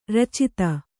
♪ racita